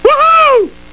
woohoo.wav